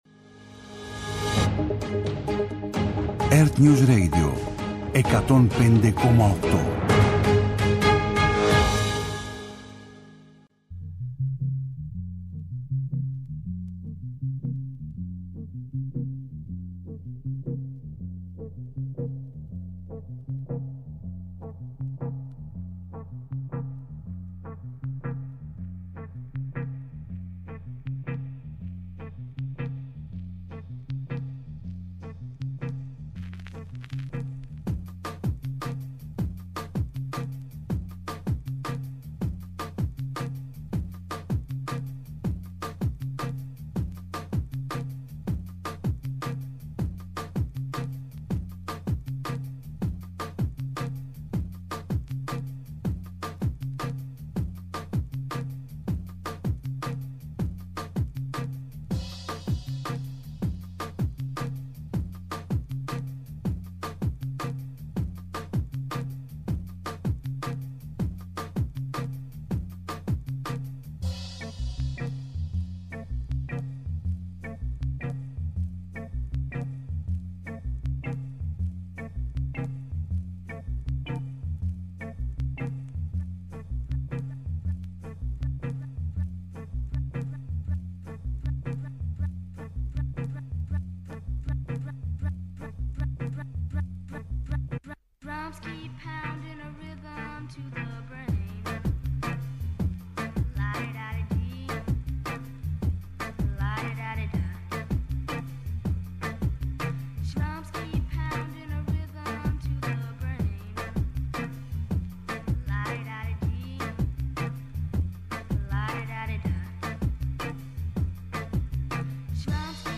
-Μετάδοση της συνέντευξης τύπου Νετανιάχου (απόσπασμα)